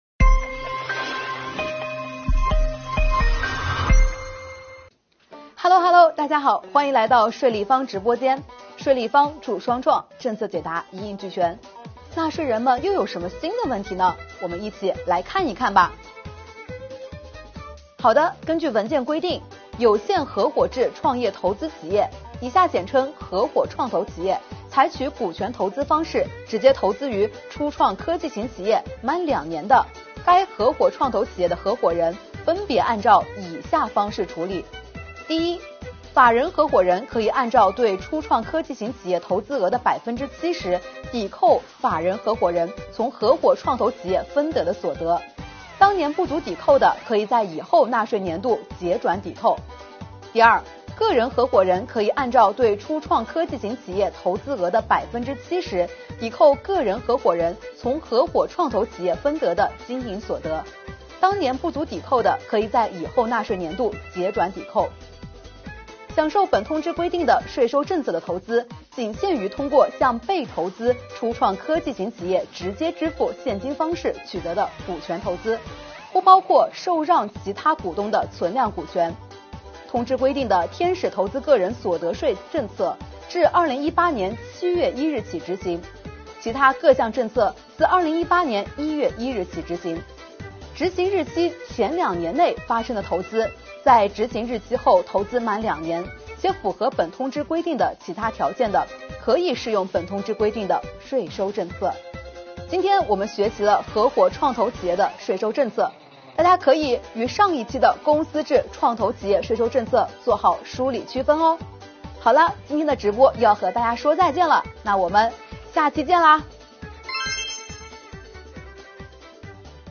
今天，税老师为您讲解：有限合伙制创投企业的税收政策!